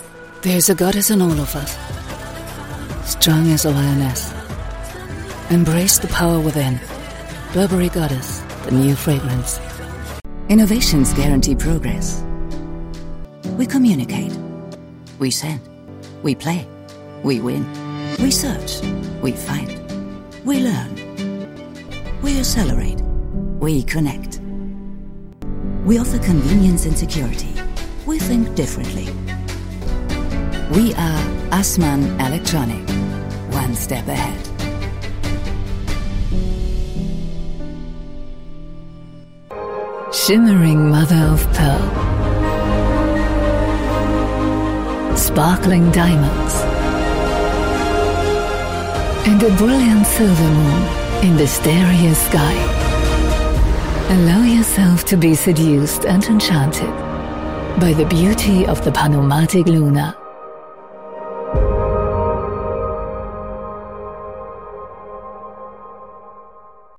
Eine Stimme, die Sie genießen werden: - warm, markant, angenehm, - sympathisch, seriös und verbindlich, - wohltuend, charmant und wandelbar.
Sprechprobe: Sonstiges (Muttersprache):